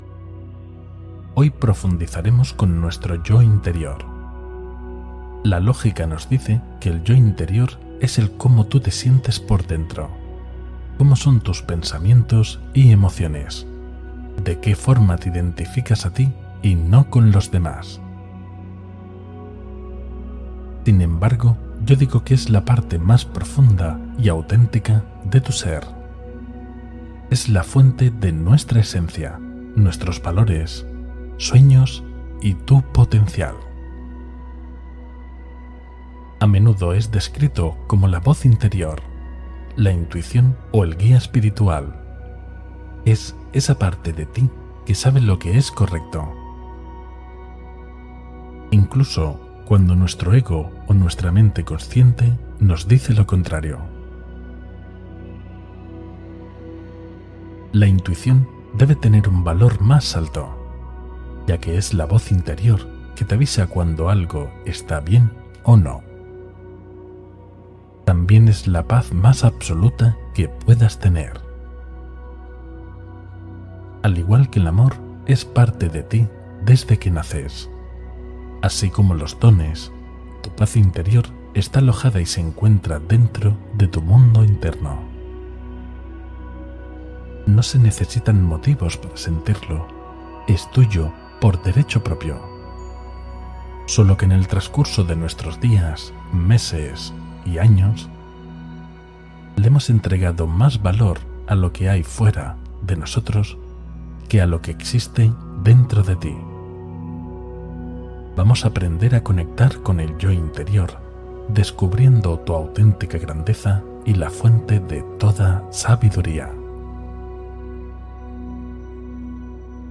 Unión con el Yo Interior: Meditación de Sanación Integrativa